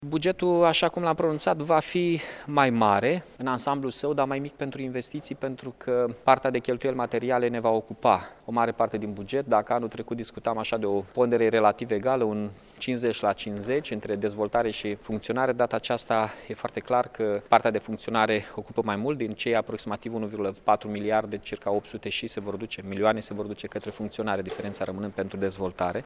Primarul municipiului Iași, Mihai Chirica a informat, astăzi, într-o conferiță de presă că, din propunerile centralizate de la cetățeni, reiese că aceștia doresc amenajarea de locuri de parcare, modernizarea transportului public, fluidizarea traficului auto și amenajarea unor locuri de joacă pentru copii și pentru petrecerea timpului liber.